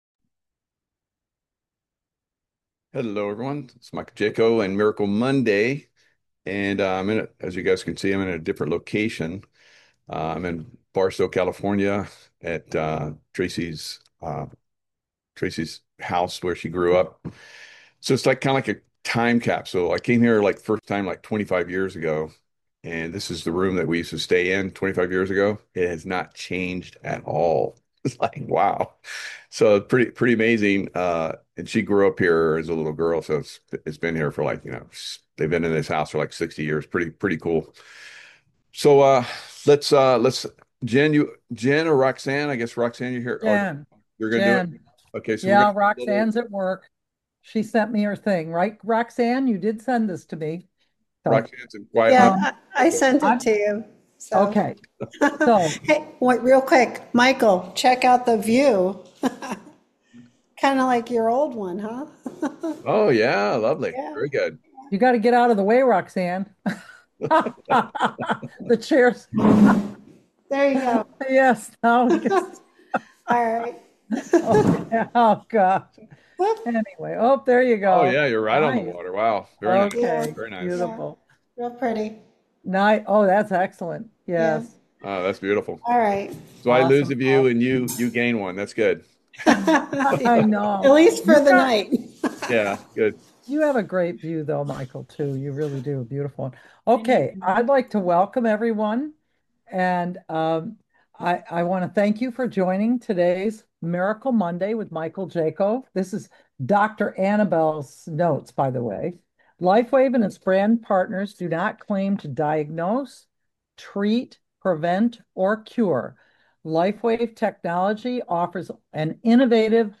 friends discuss Lifewave patches, which use light to boost wellness. These patches can help with pain, sleep, and energy.